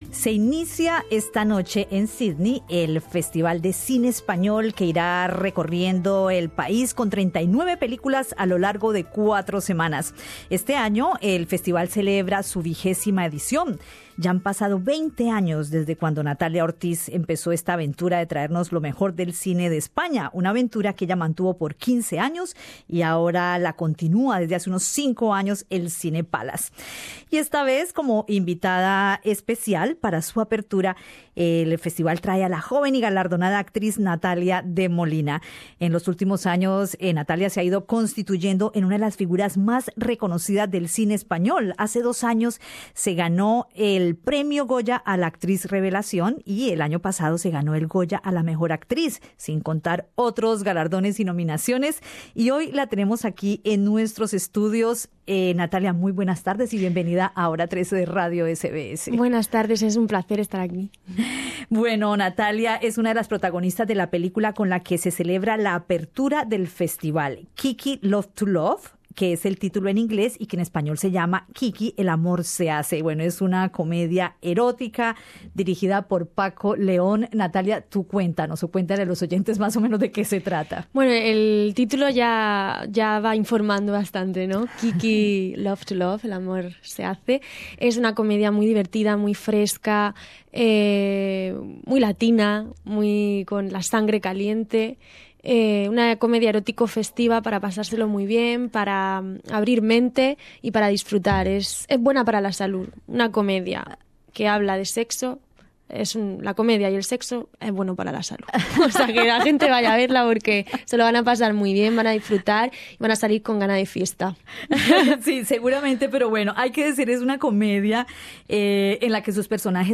Con Natalia de Molina conversamos en los estudios de Radio SBS.